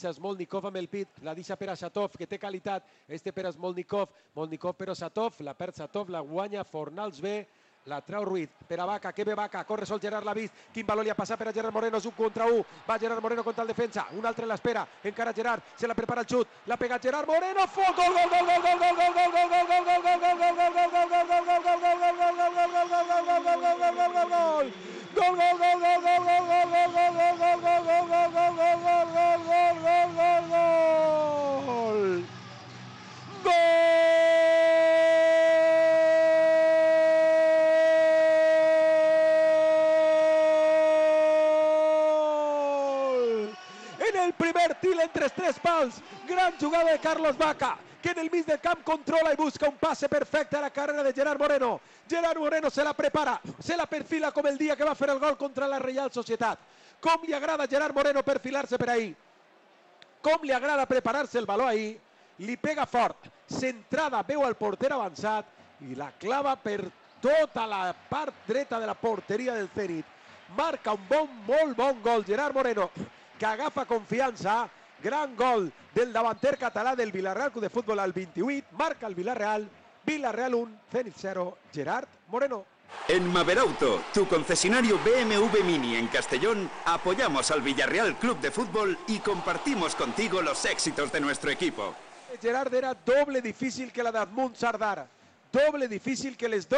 Disfruta del momentazo escuchando los 2 goles del Villarral C.F.
1-GOL-VILLARREAL-C.F.mp3